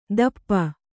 ♪ dappa